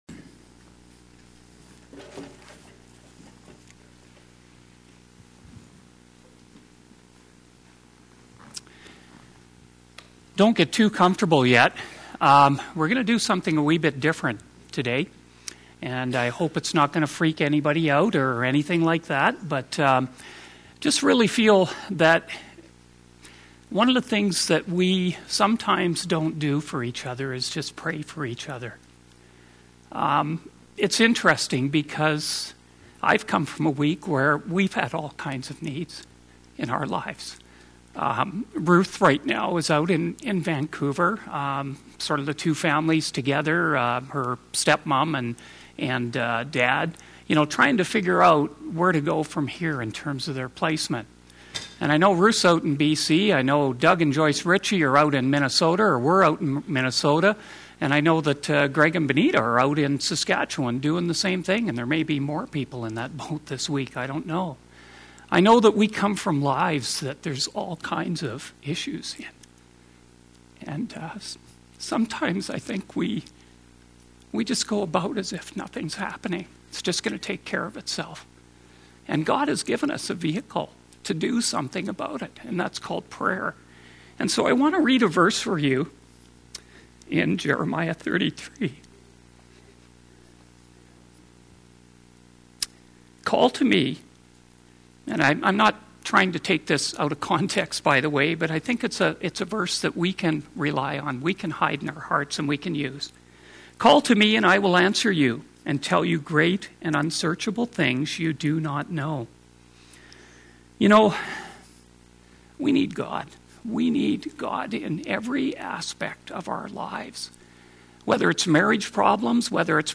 Sermons Archive - Page 58 of 63 - Rocky Mountain Alliance Church